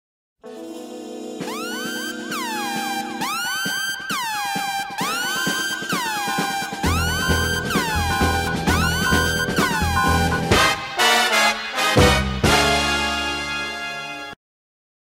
• Качество: 128, Stereo
тревожные
Завораживающая мелодия из известного фильма